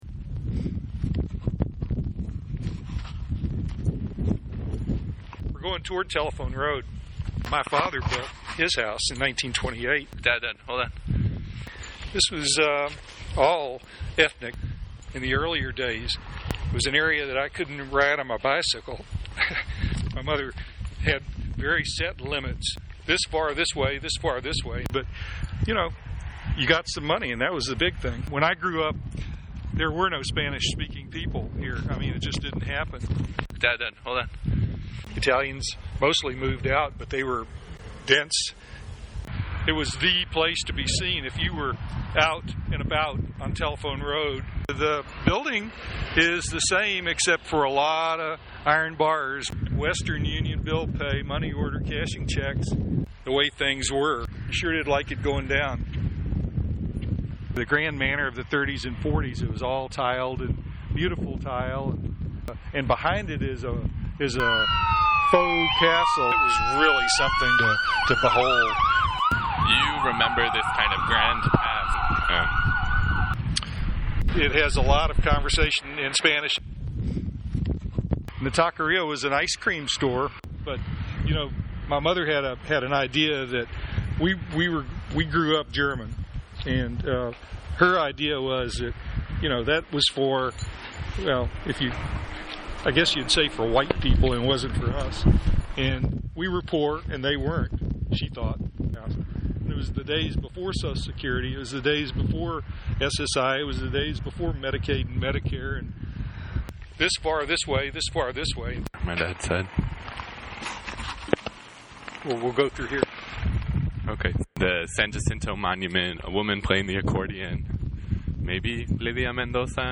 audio walking tours
Not so much a tour to make things more legible, but a remnant of a walk, an audio piece standing in for the lack of a tour.